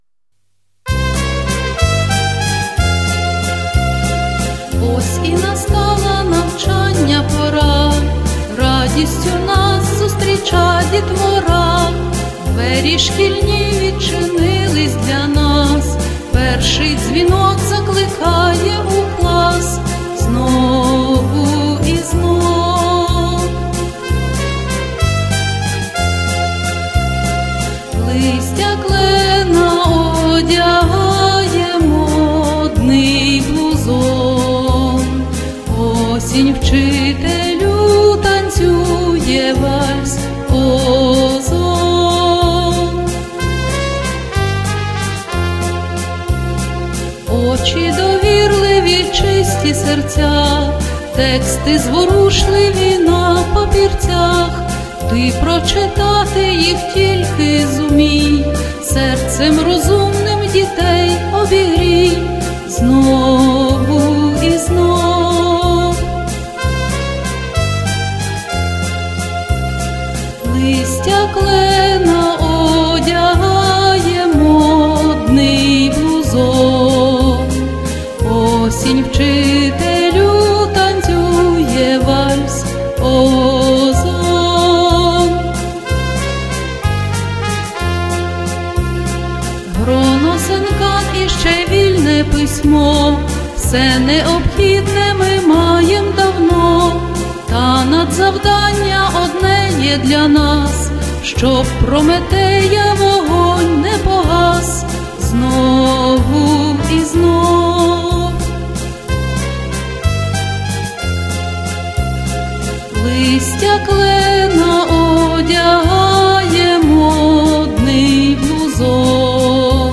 Вальс ОЗОН